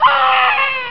Scream+5